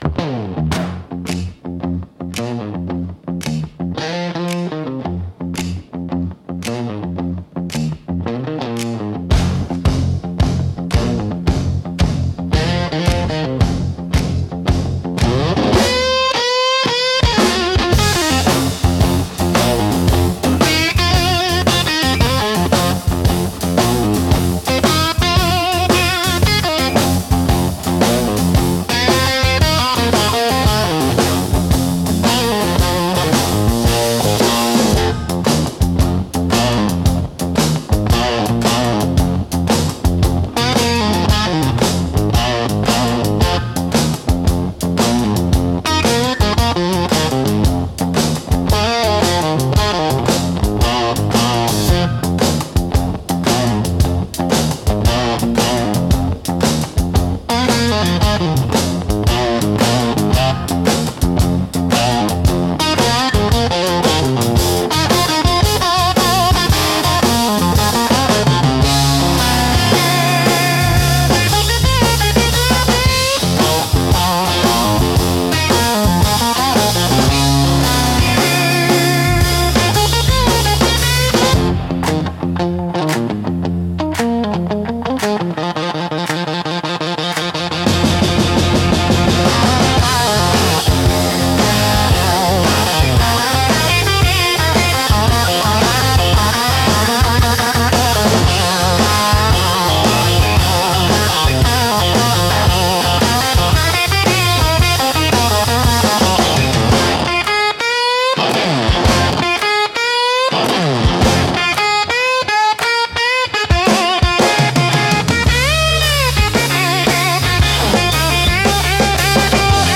Instrumental - Tremolo and Twilight